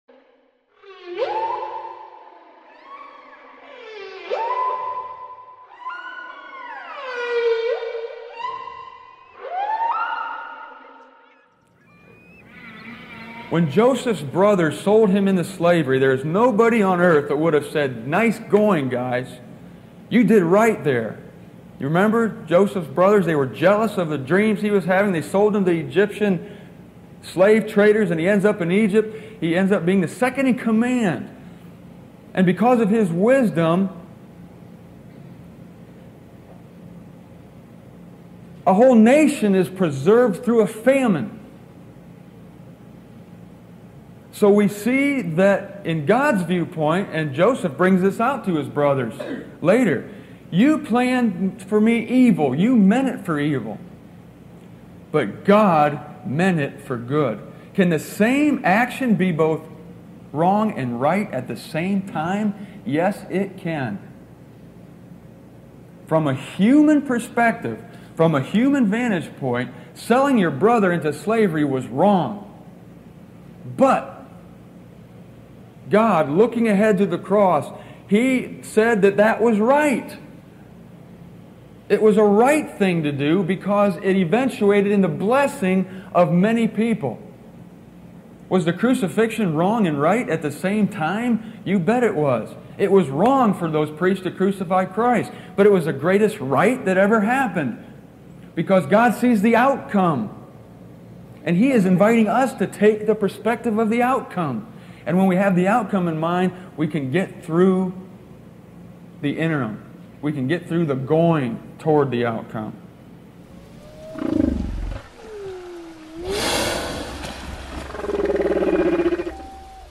It was recorded in 1996.